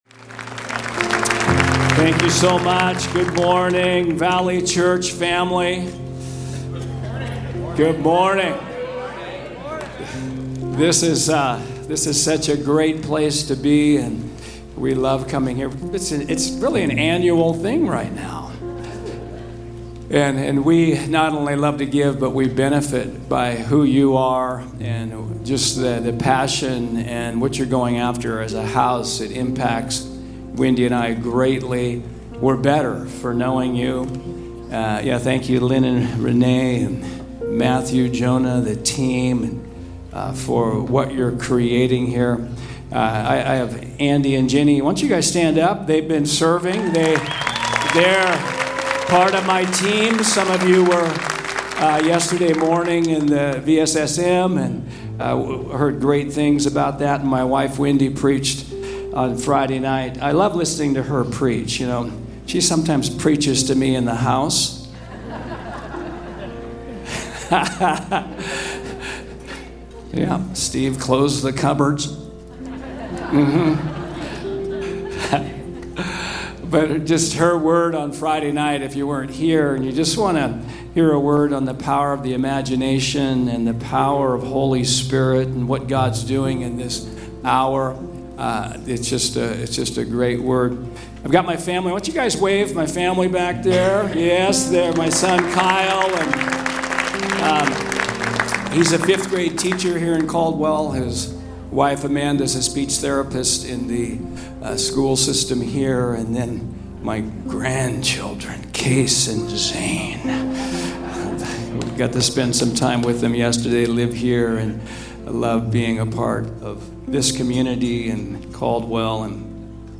Message from Guest Speaker